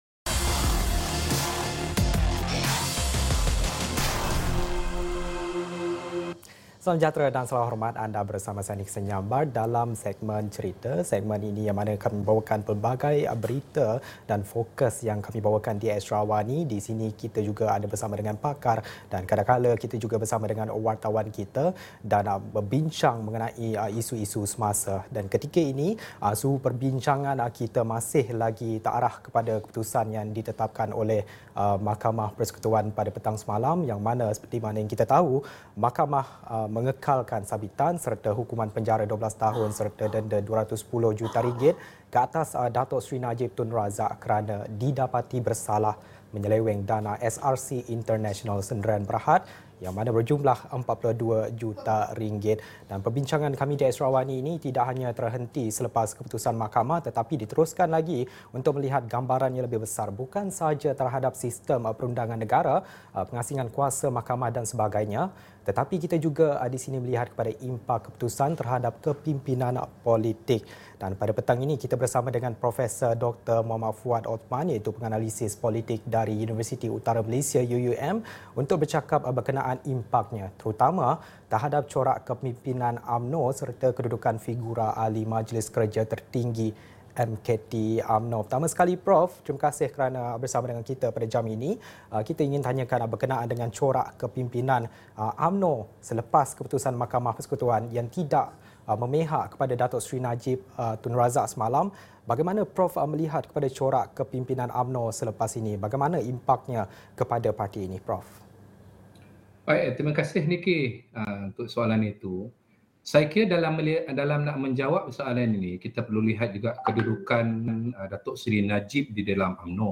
temu bual